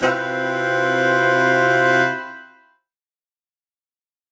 UC_HornSwellAlt_Cmaj7b5.wav